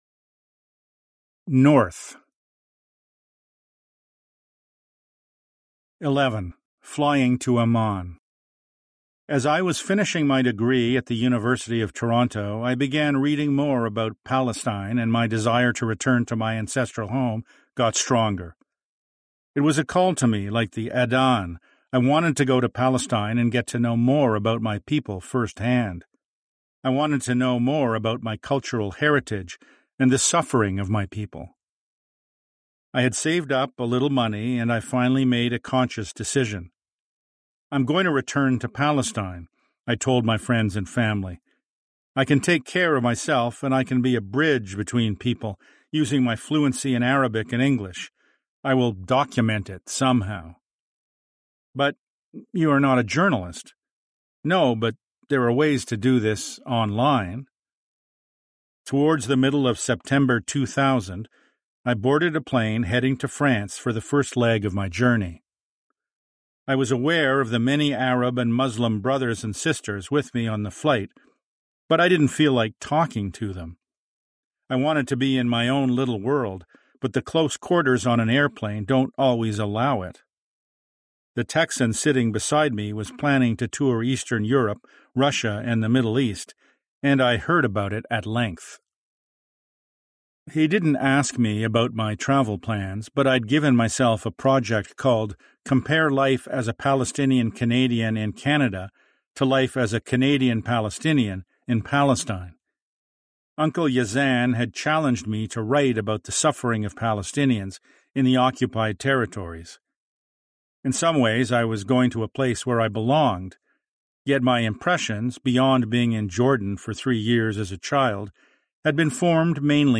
Under the Nakba Tree: Fragments of a Palestinian Family in Canada (Audio Book) - Front Matter